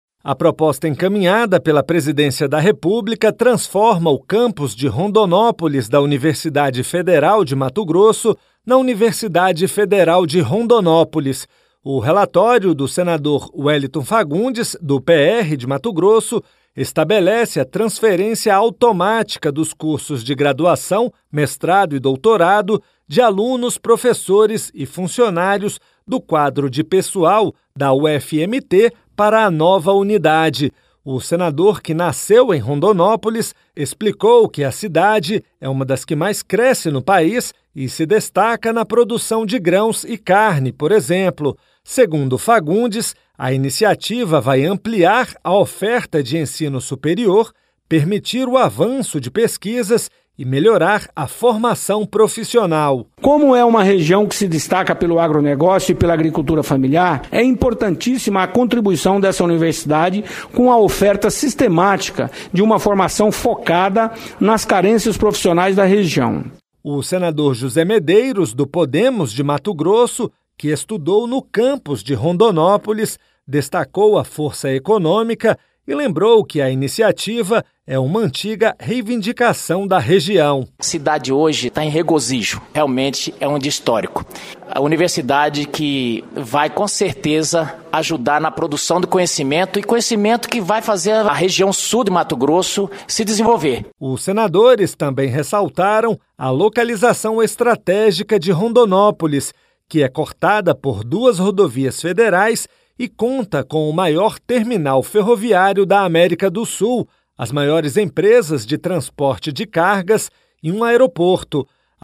Rádio Senado - Ao Vivo